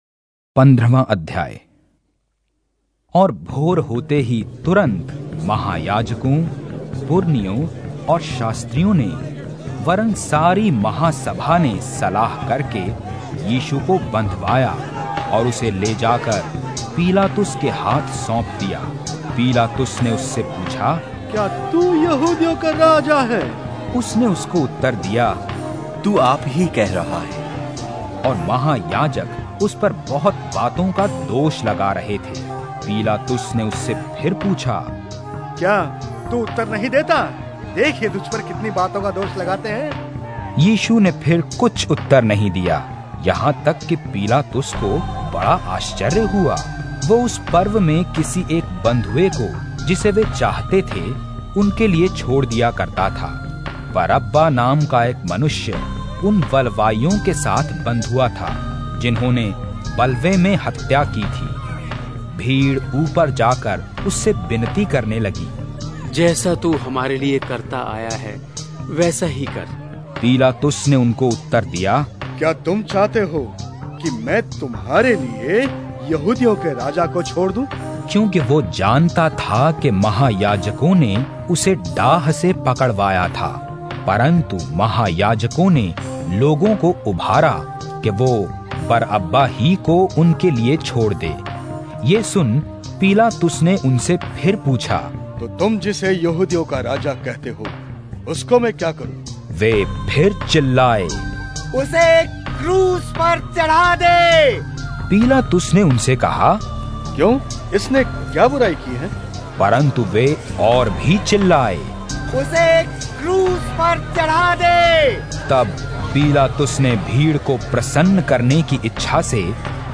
Hindi Drama Audio Bible New Testament